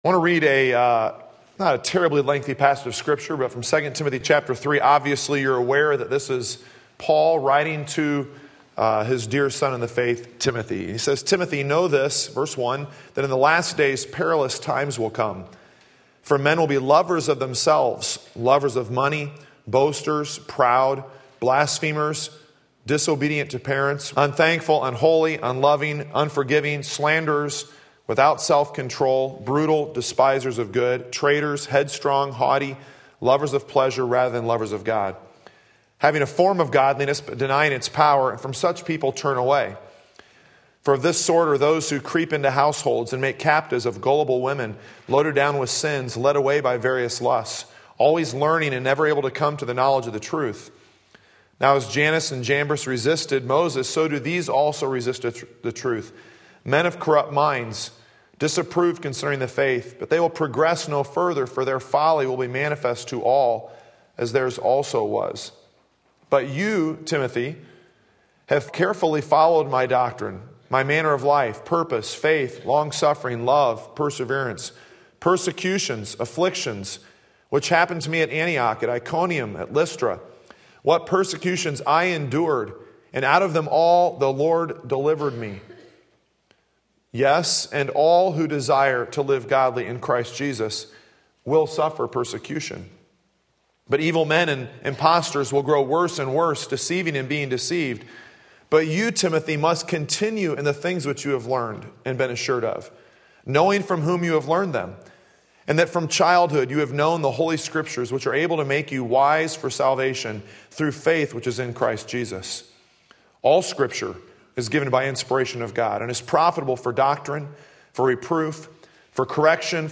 Sermon Link
Remember 2 Timothy 3:10-17 Sunday Morning Service